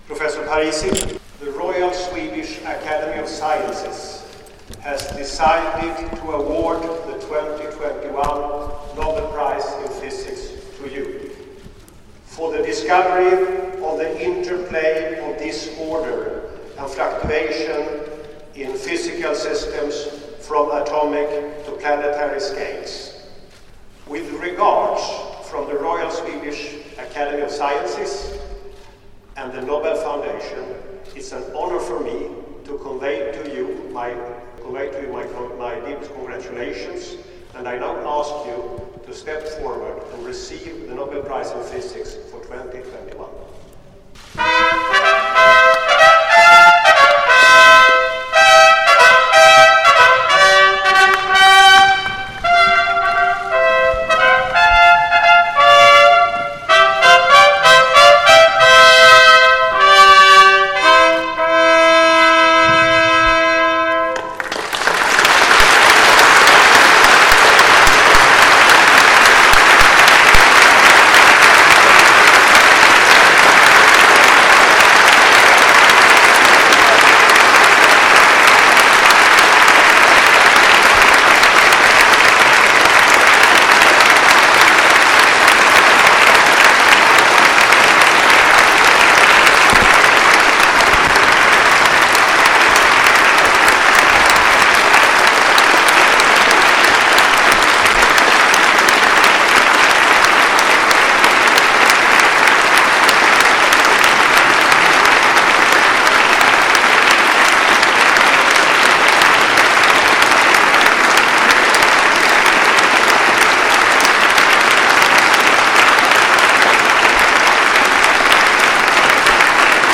È stata una cerimonia molto più formale quella di lunedì 6 dicembre alla Sapienza (Aula Magna) rispetto al quel 5 ottobre nella stessa facoltà quando – appena saputo del Nobel – il Prof. Parisi era stato accolto dai suoi studenti con tifo da stadio e striscione annesso con un It’s coming Rome che resterà nella storia dell’Ateneo.